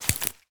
Minecraft Version Minecraft Version latest Latest Release | Latest Snapshot latest / assets / minecraft / sounds / block / cactus_flower / break1.ogg Compare With Compare With Latest Release | Latest Snapshot
break1.ogg